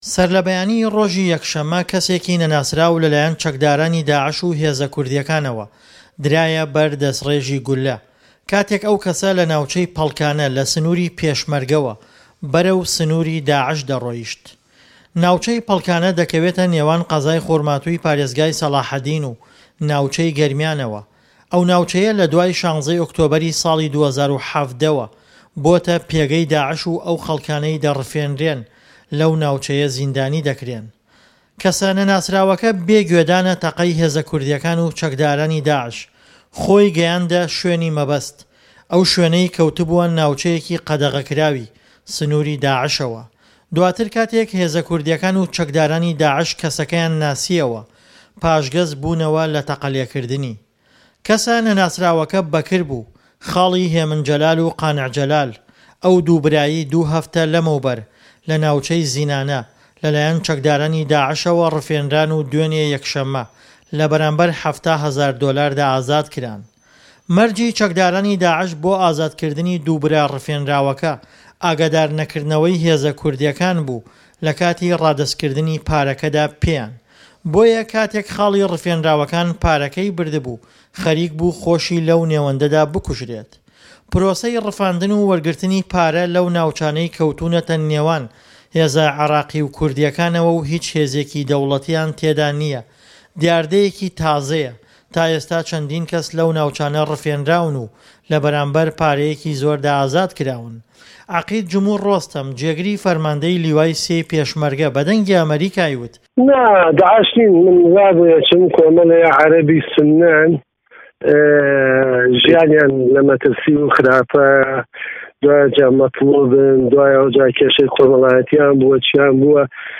ڕاپـۆرتی